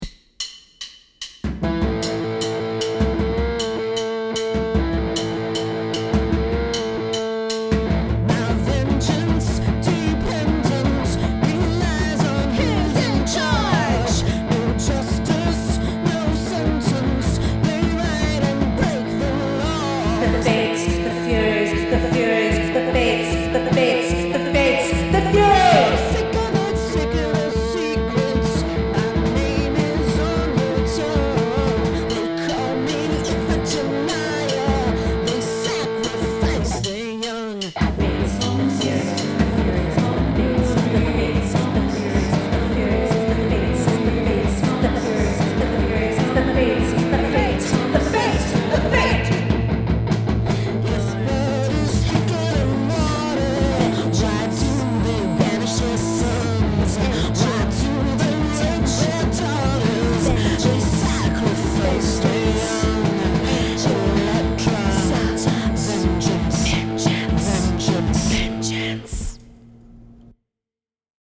Liars & Believers transforms ancient, divine justice into contemporary, thrashing vengeance – in a new theatrical adaptation with driving text, kinetic physicality, and a Riot-Grrrl punk band.
These are the first demo recordings